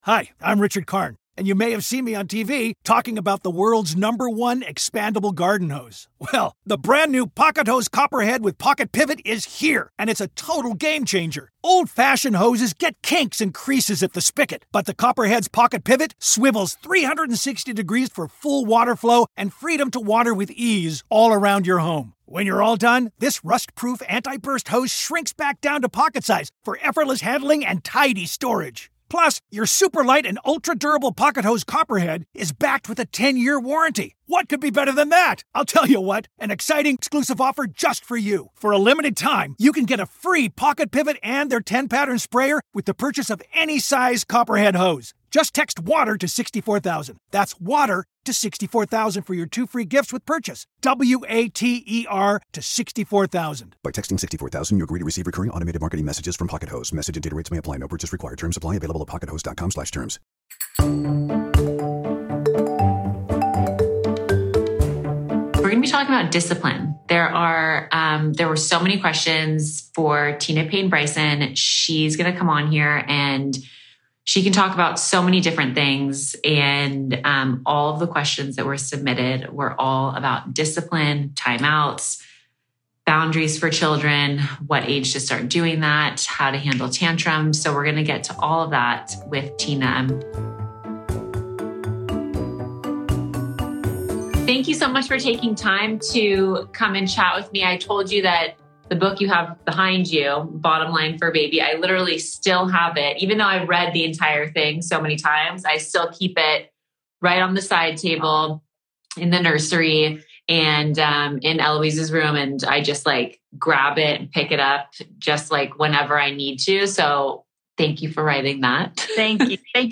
Dr. Tina Payne Bryson joins us this week to share some great advice on discipline and setting boundaries with our kids.